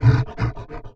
MONSTER_Exhausted_02_mono.wav